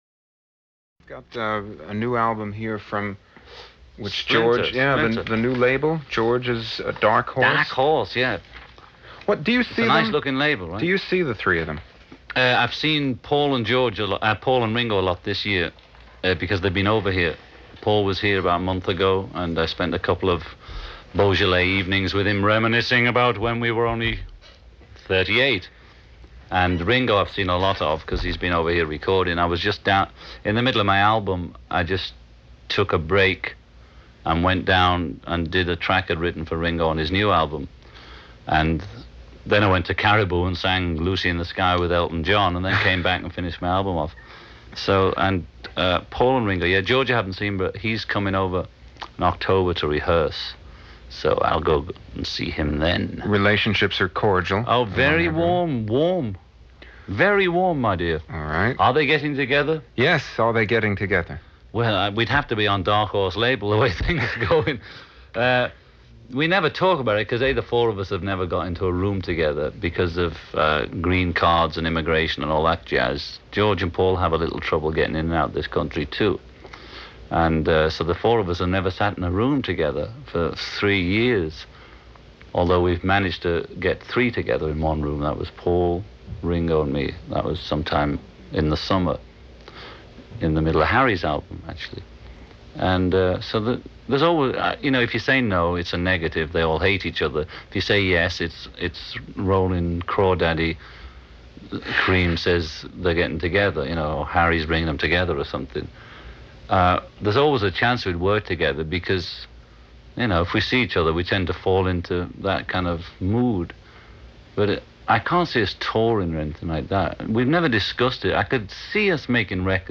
07-wnew-interview_-on-the-beatles.mp3